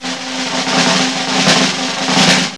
Snares
GVD_snr (17).wav